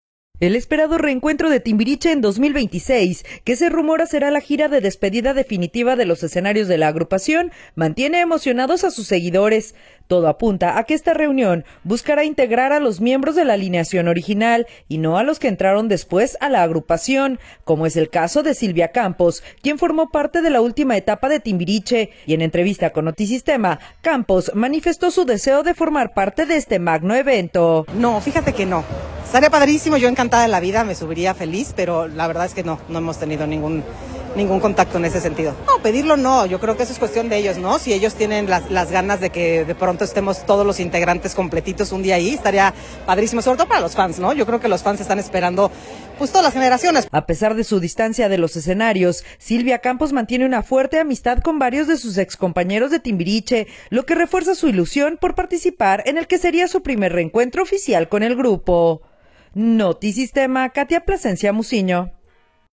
nota-timbiriche.m4a